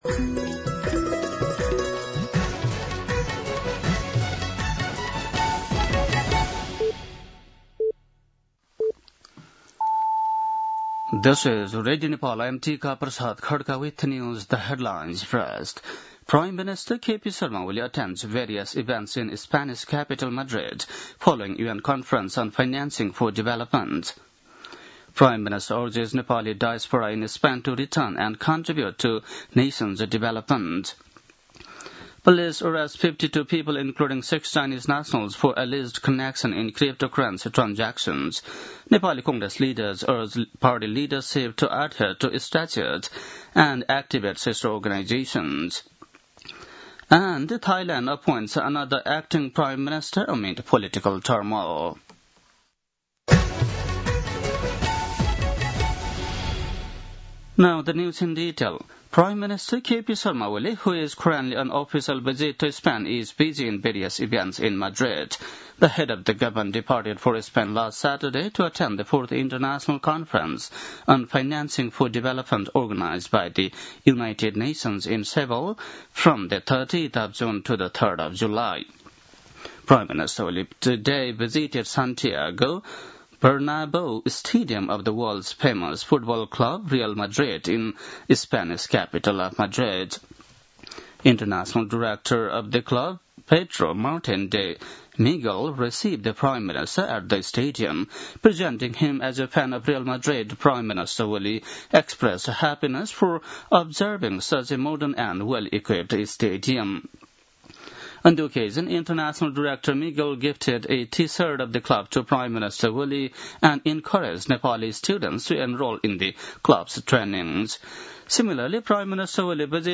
बेलुकी ८ बजेको अङ्ग्रेजी समाचार : १९ असार , २०८२
8-pm-news-.mp3